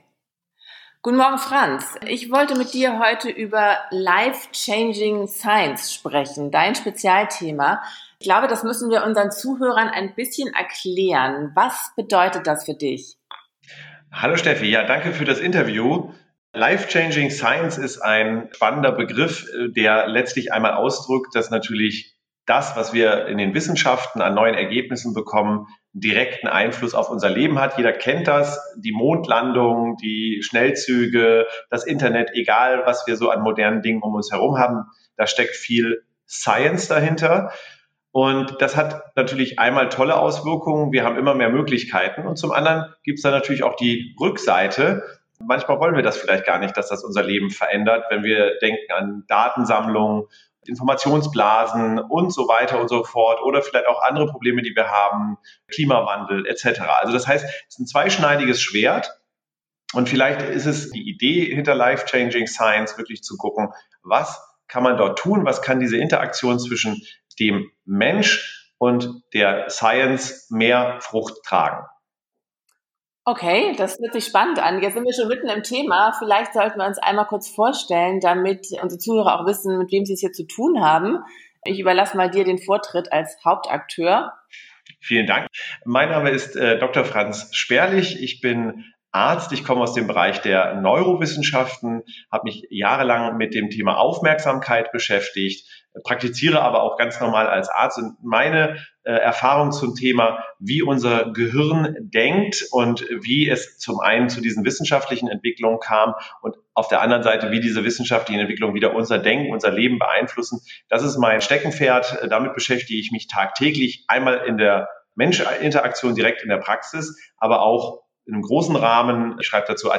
podcast-interview-life-changing-science.mp3